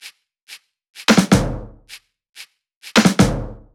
VFH3 128BPM Resistance Kit 4.wav